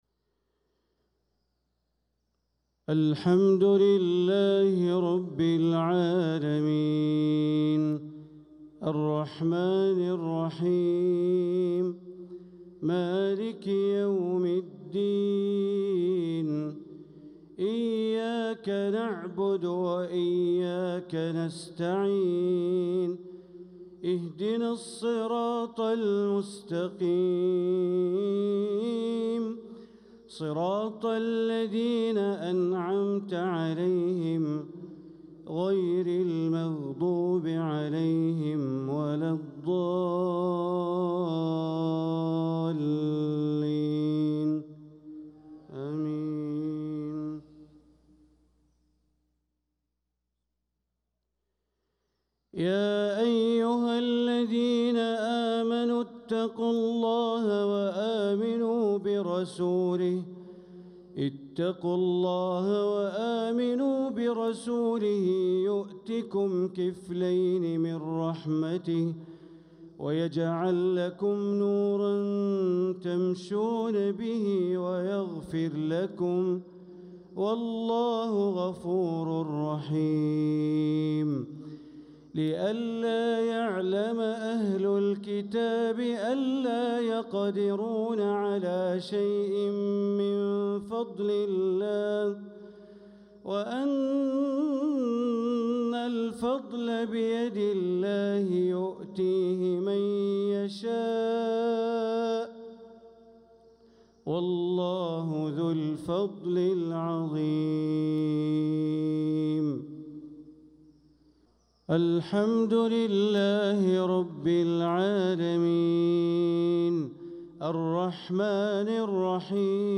صلاة المغرب للقارئ بندر بليلة 22 صفر 1446 هـ
تِلَاوَات الْحَرَمَيْن .